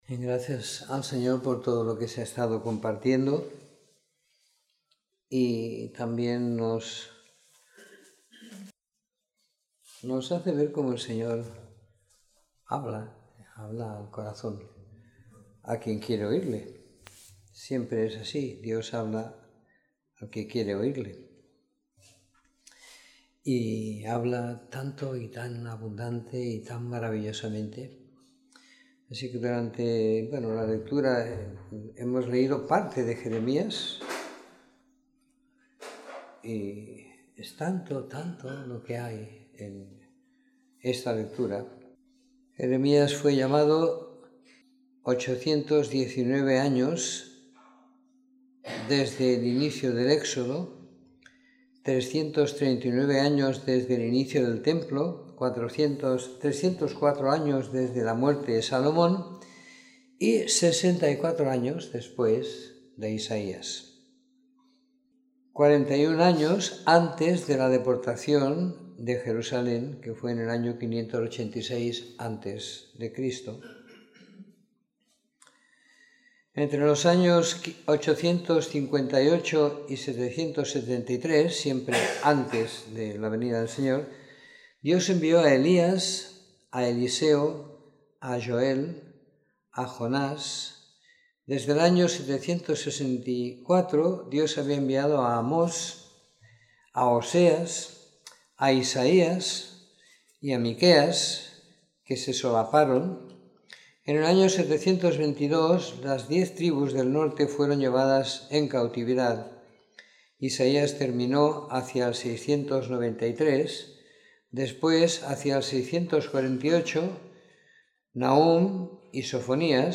Comentario en el libro de Jeremías del capítulo 1 al 30 siguiendo la lectura programada para cada semana del año que tenemos en la congregación en Sant Pere de Ribes.